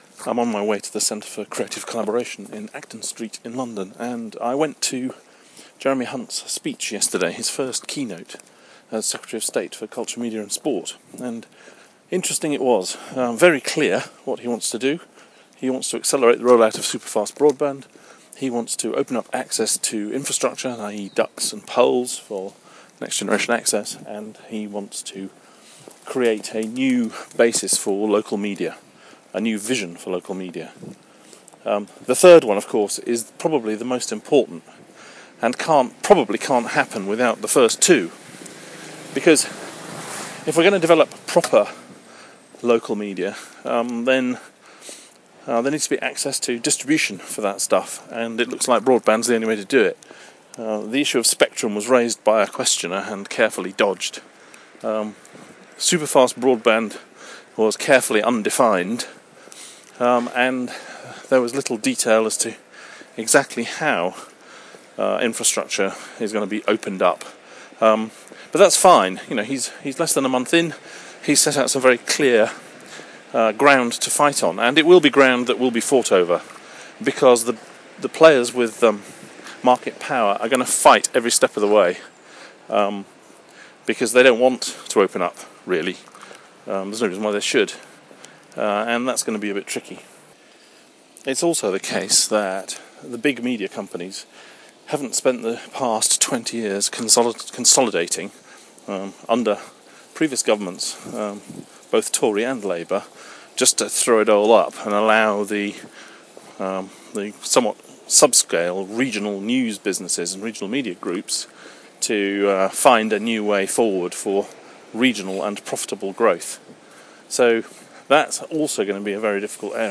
A vision for local media - Jeremy Hunt's speech